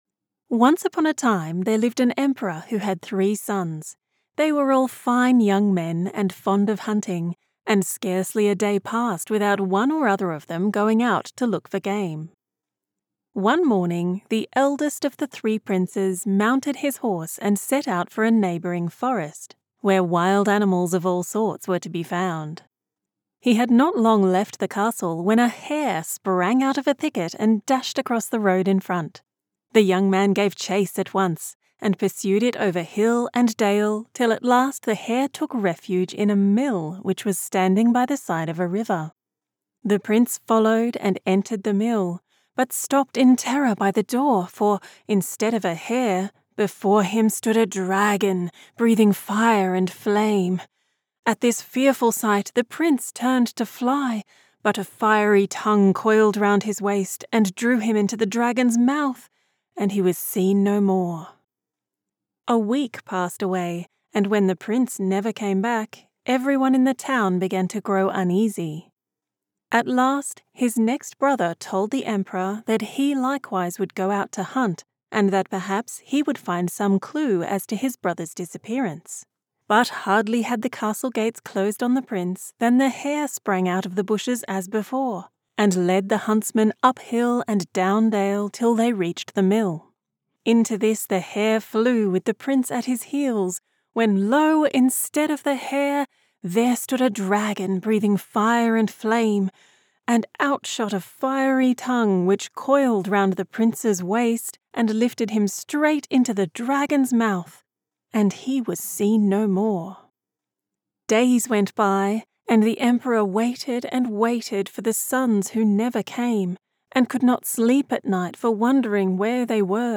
Female
Childrens Audiobook Fairytale
Words that describe my voice are Medical narration expert, Warm and comfortable, Authentic Australian.